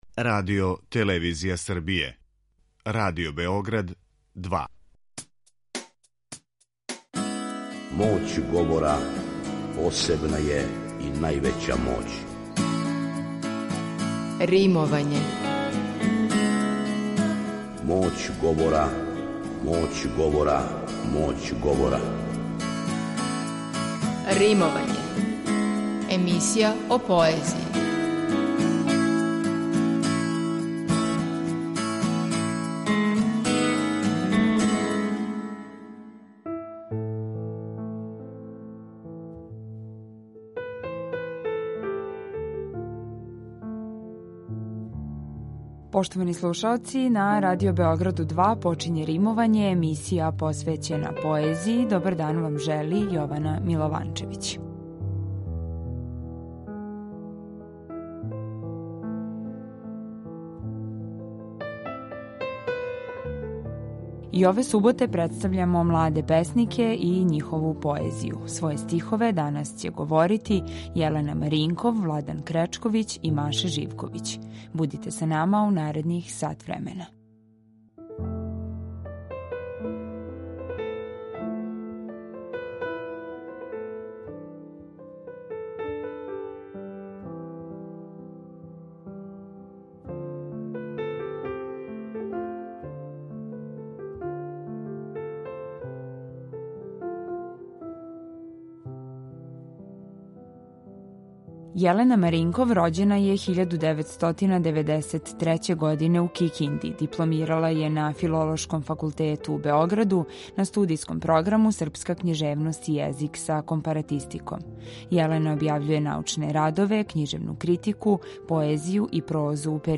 У данашњем Римовању настављамо да представљамо младе песнике. Ове суботе своје стихове говориће: